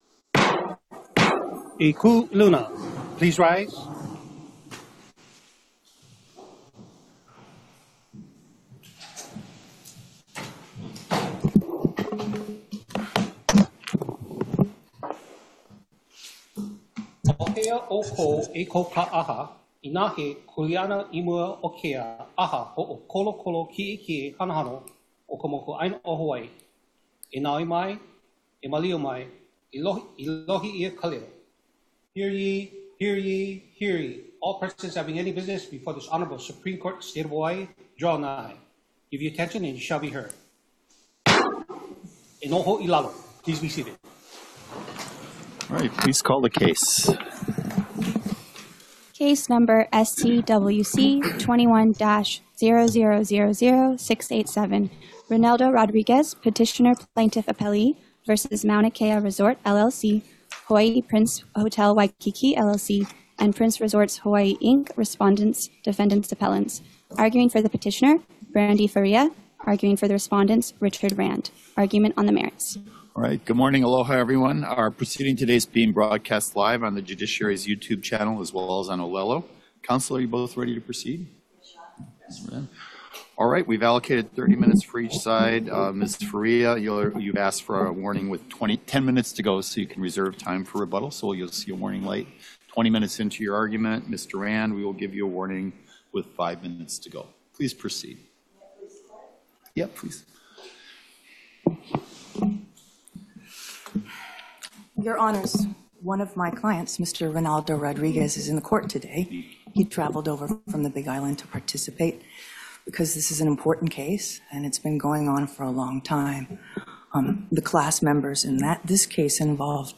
The above-captioned case has been set for oral argument on the merits at: